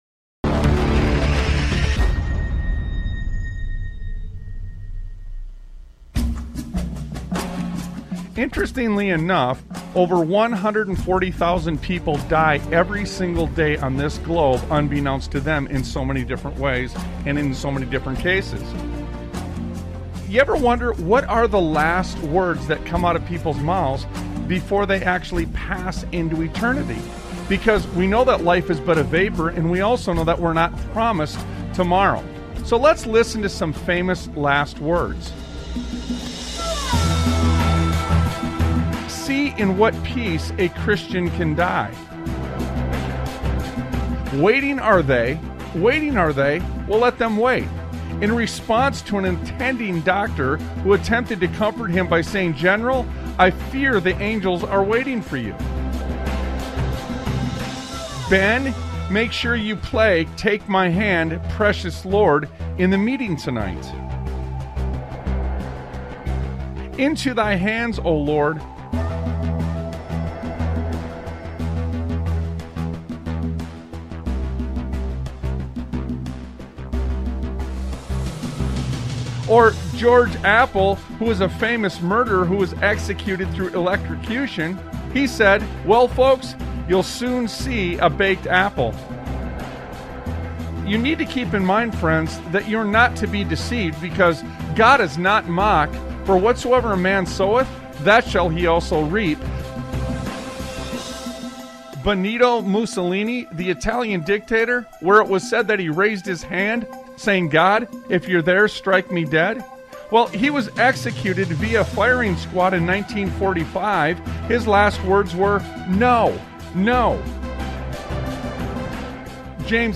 Sons of Liberty Radio Talk Show